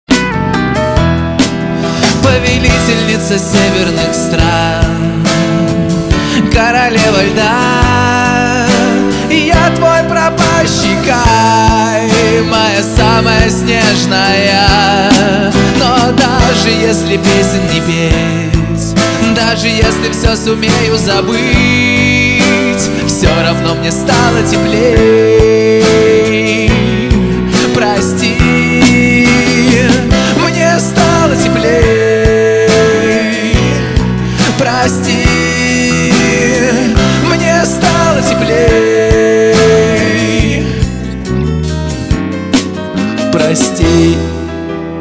• Качество: 320, Stereo
лирика
рок
Лирическая композиция